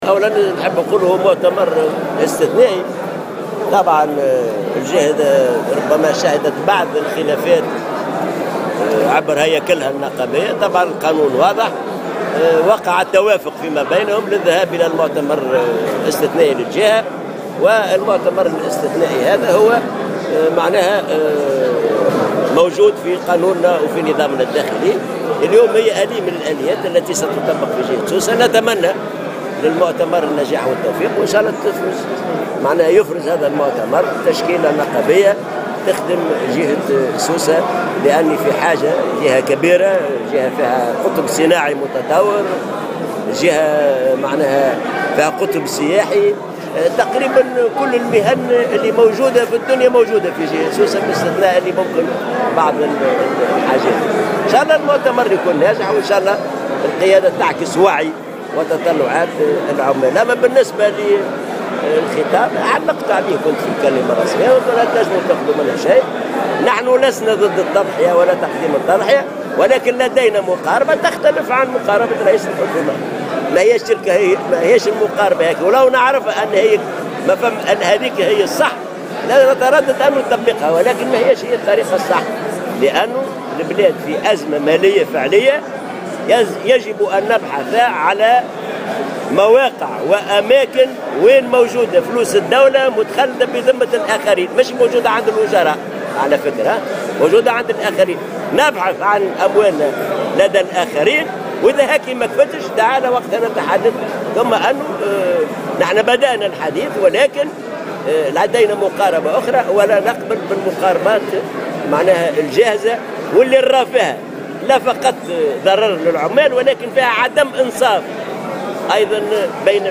اعتبر الأمين العام لإتحاد الشغل حسين العباسي في تصريح للجوهرة "اف ام" اليوم الخميس 29 سبتمبر 2016 على هامش انعقاد أشغال المؤتمر الإستثنائي أن تصريح يوسف الشاهد حول إرجاء الزيادة في الأجور الى سنة 2019 مقاربة غير منصفة للطبقة الشغيلية و الأجراء.